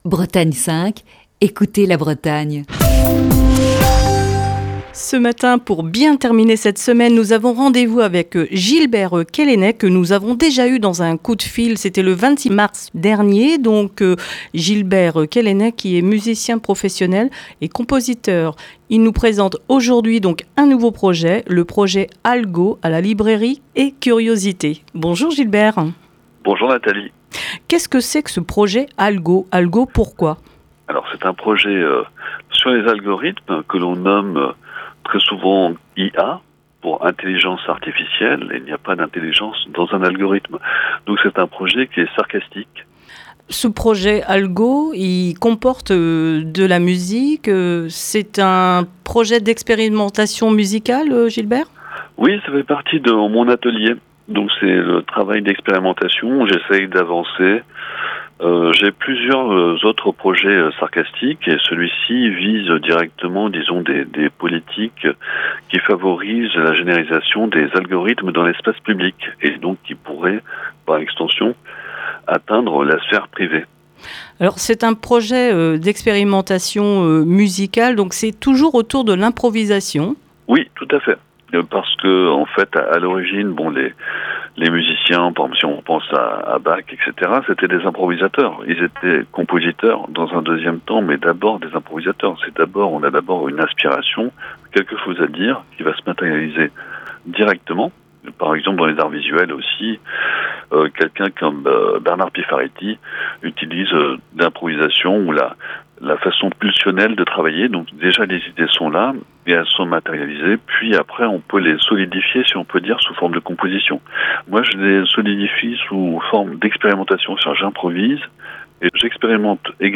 Dans le coup de fil du matin de ce vendredi